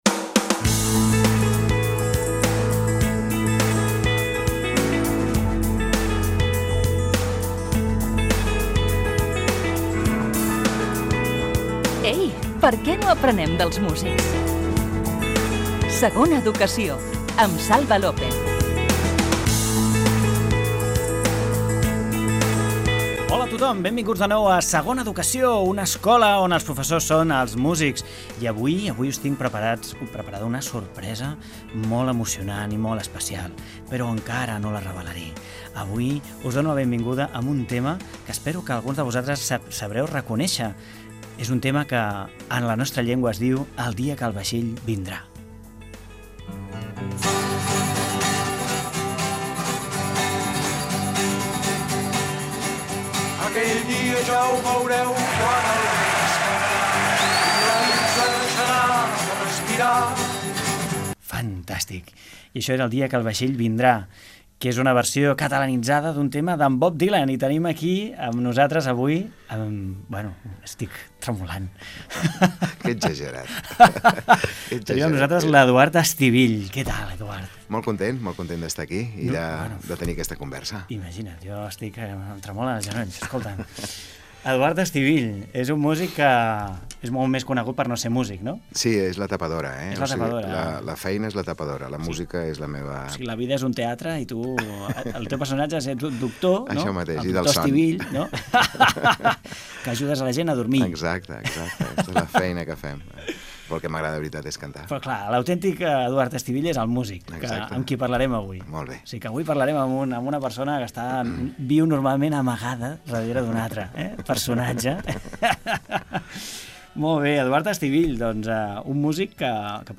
Careta del programa, presentació i fragment d'una entrevista amb el metge i músic Eduard Estivill.
Musical